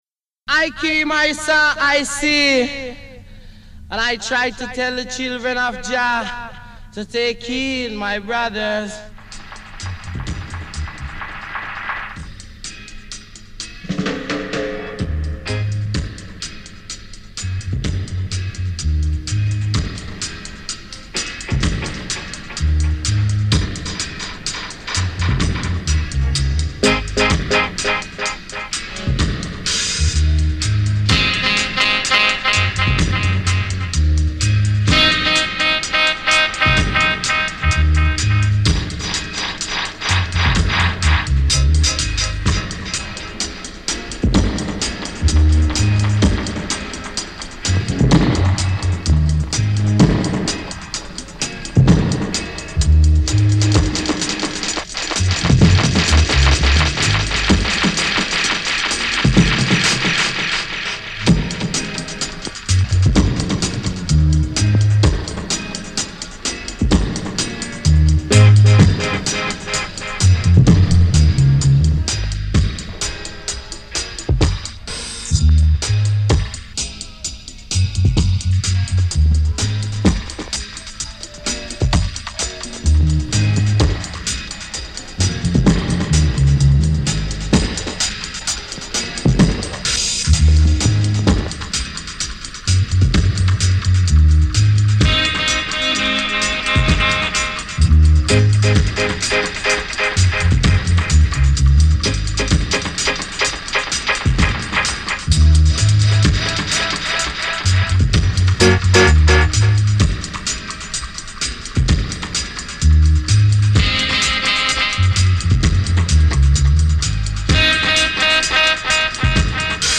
A version excursion!